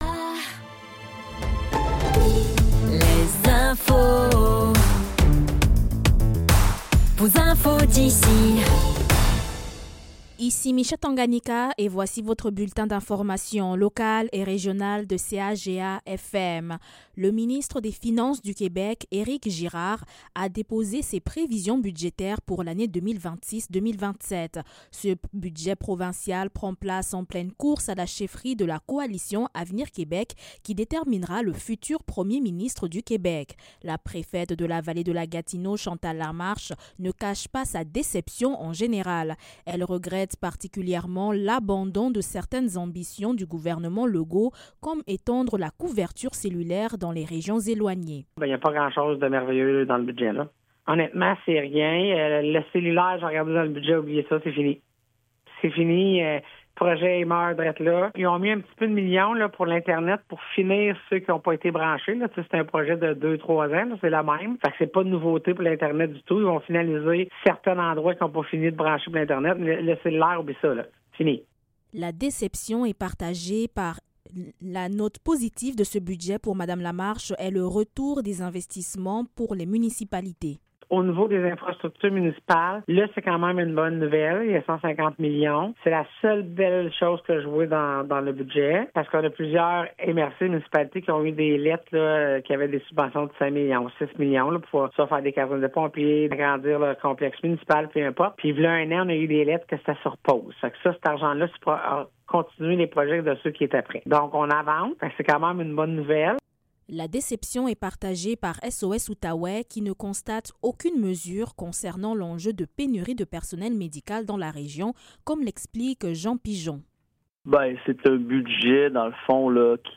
Nouvelles locales - 19 mars 2026 - 15 h